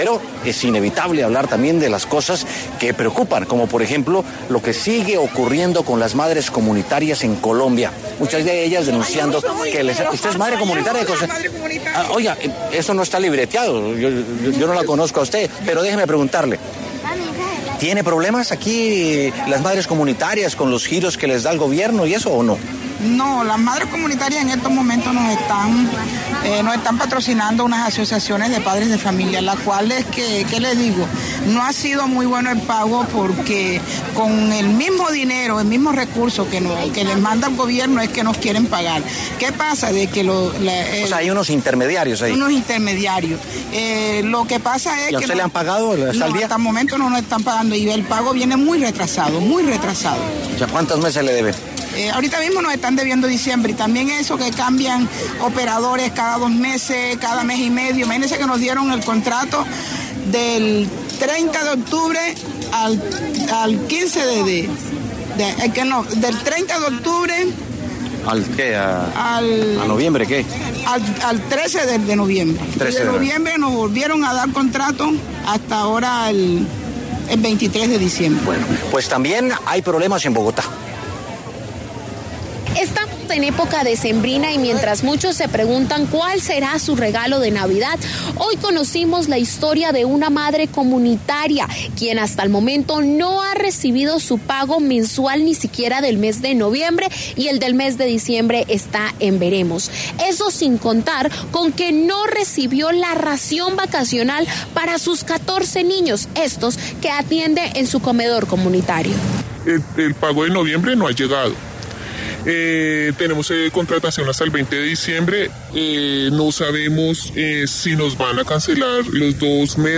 Una de ellas, habló de forma anónima en W Sin Carreta y expresó lo que, en su caso, está pasando.